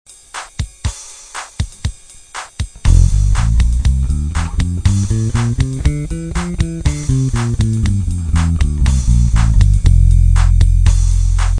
Cliquer sur Ex et vous  aurez un exemple sonore en F (Fa).
ionien